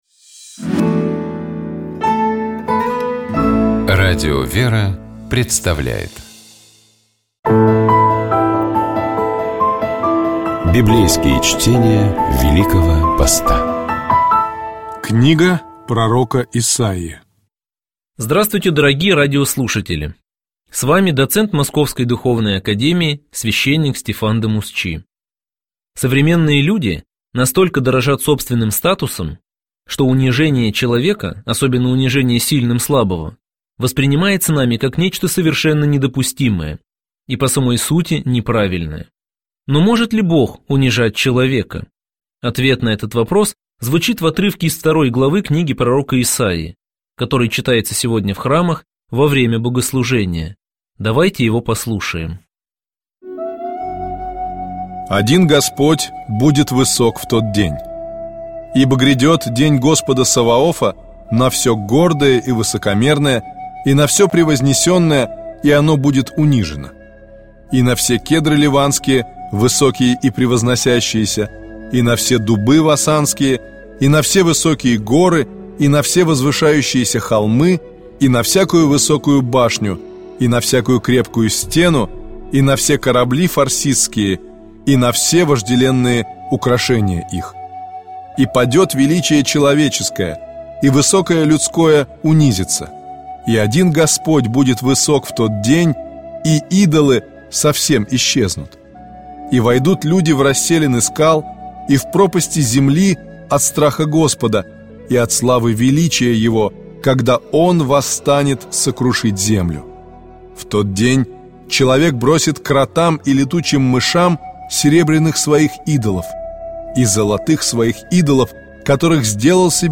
Библейские чтения
Читает и комментирует